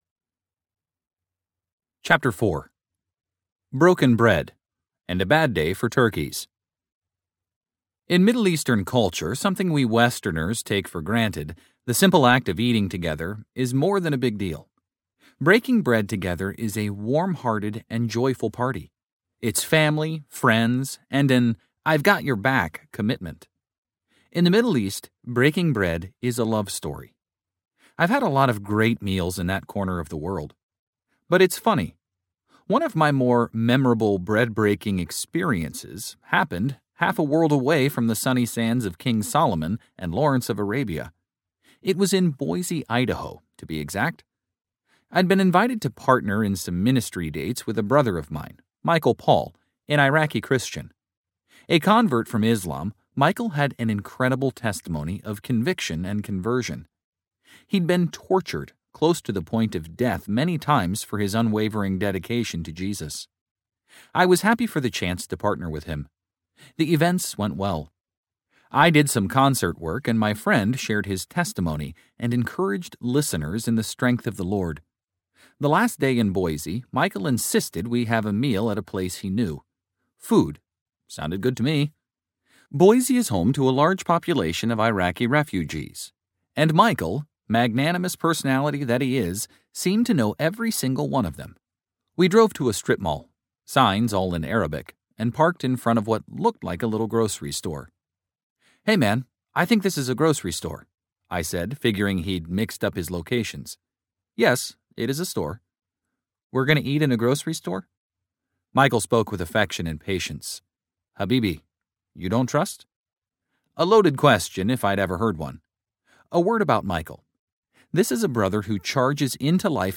Finding Jesus in Israel Audiobook
Narrator